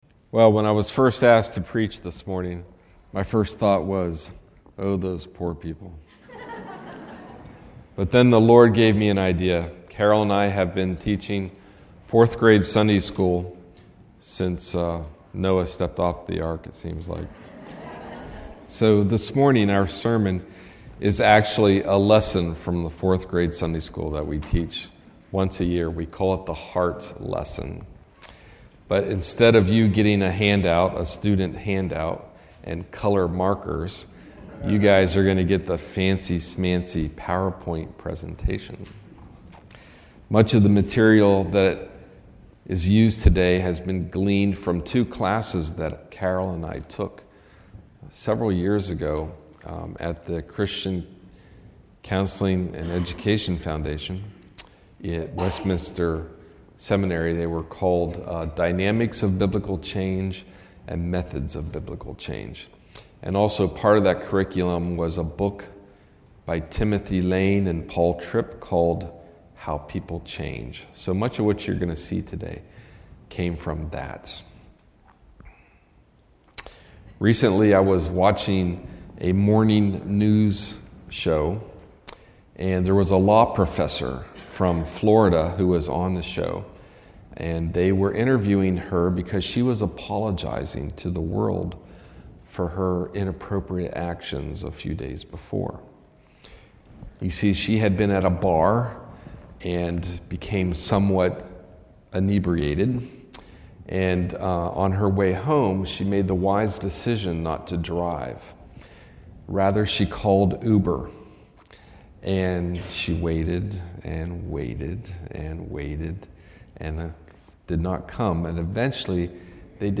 MP3 audio sermons from Brick Lane Community Church in Elverson, Pennsylvania.